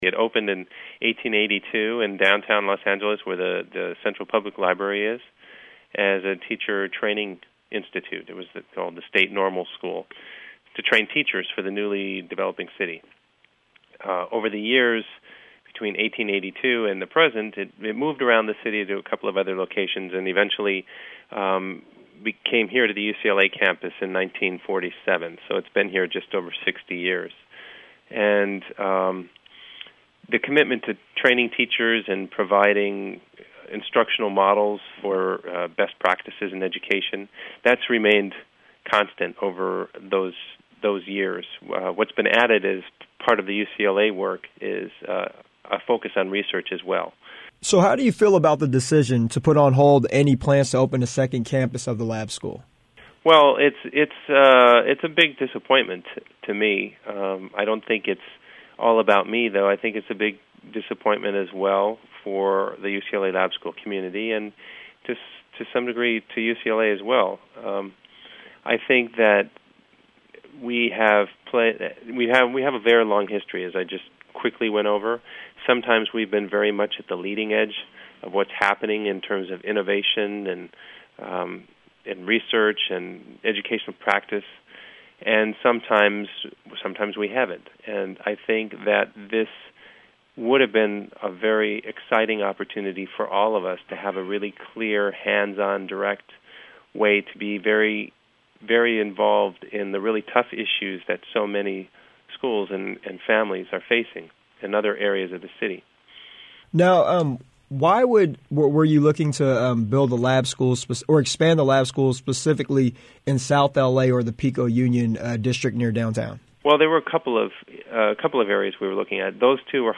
Host Interview